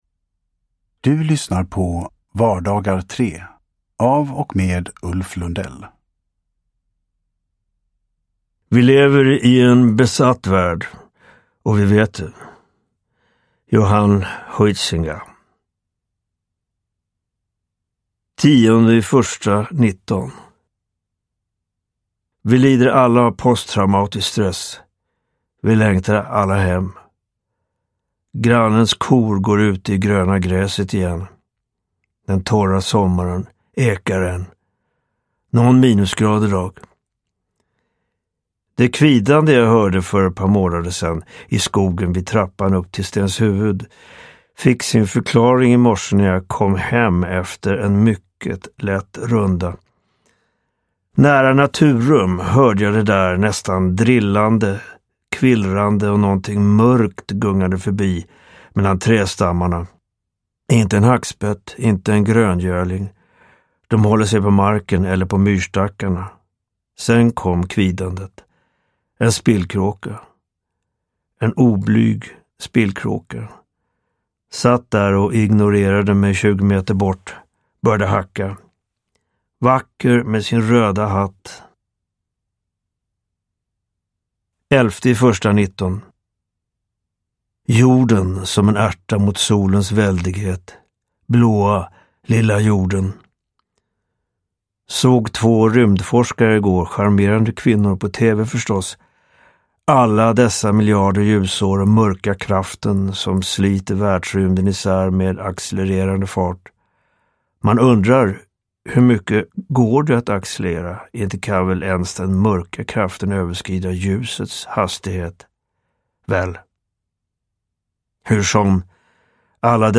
Vardagar 3 – Ljudbok – Laddas ner
Uppläsare: Ulf Lundell